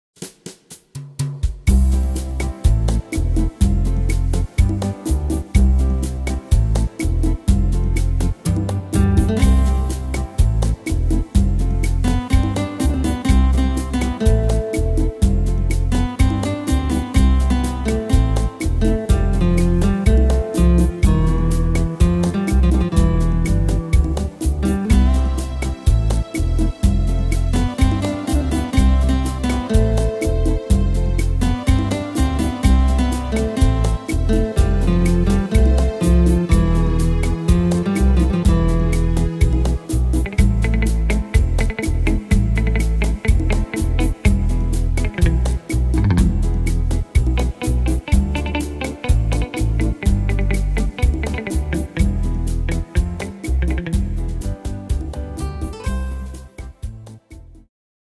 Tempo: 124 / Tonart: C-Dur